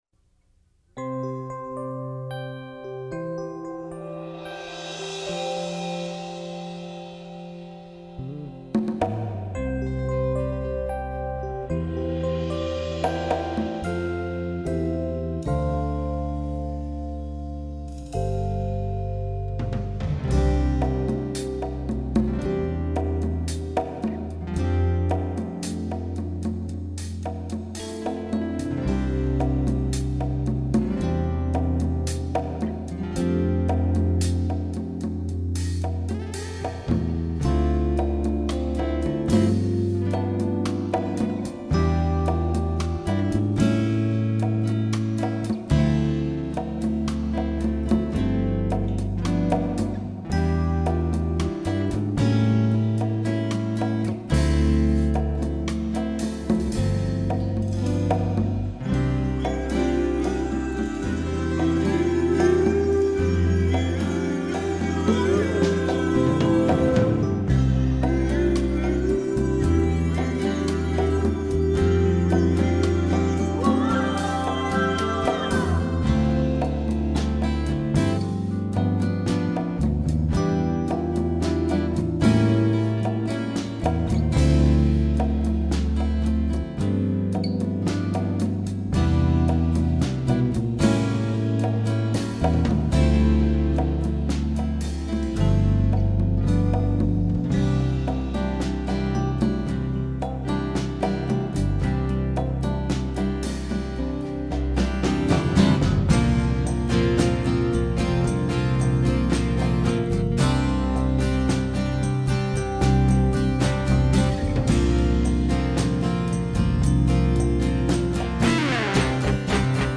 (back-track)
bass/acoustic/electric guitar
drums/percussion